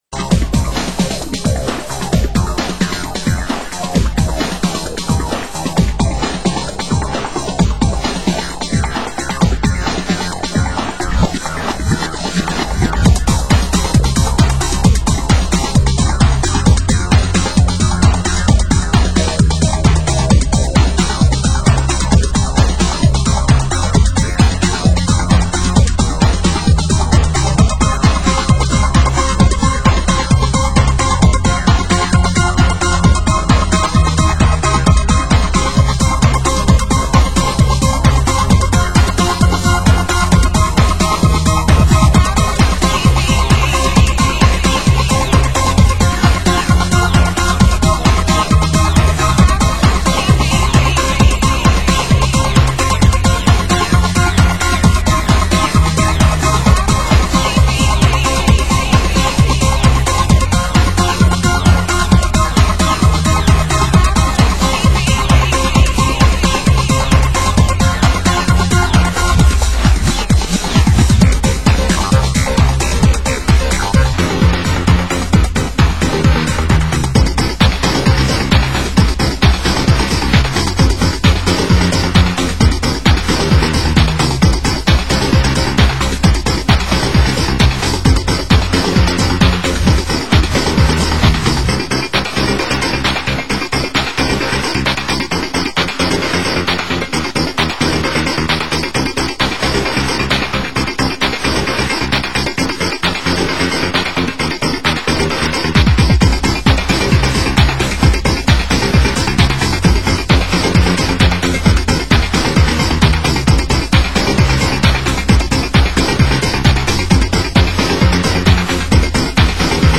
Format: Vinyl 12 Inch
Genre: UK House